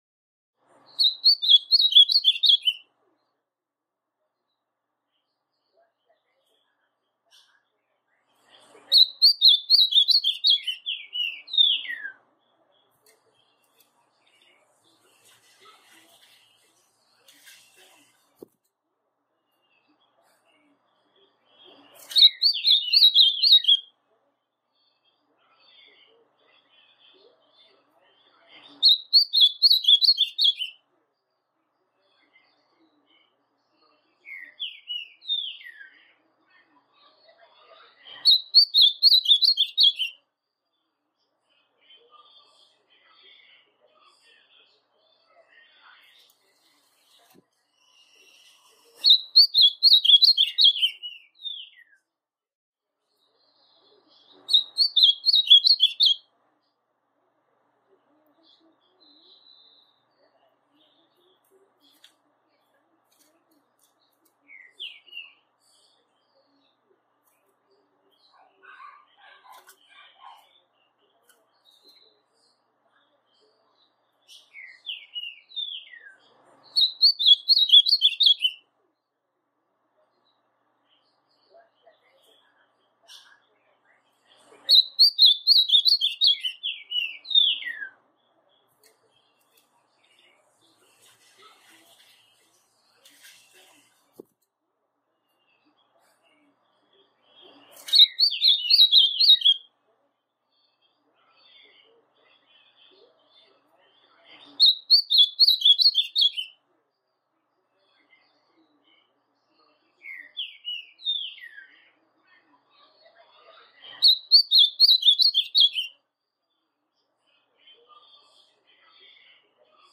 Azulão Pardo, coisa linda cantando